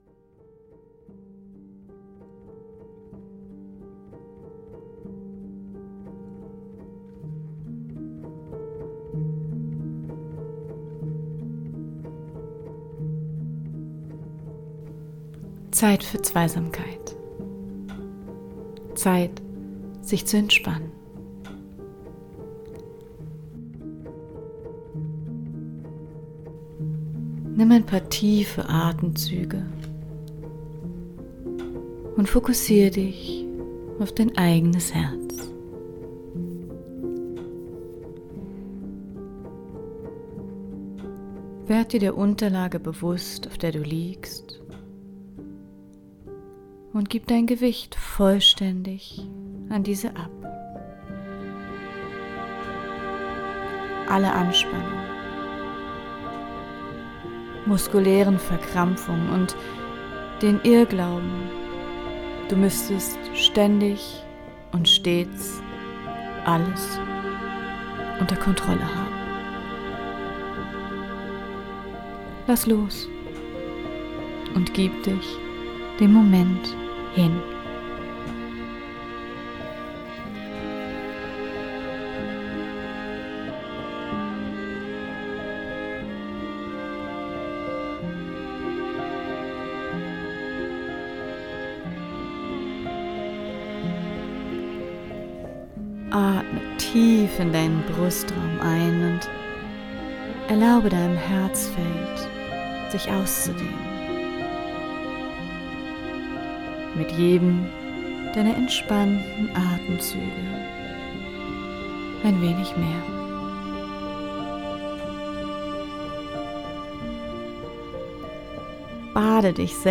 Affirmationen für zwischendurch
Musik von Pixabay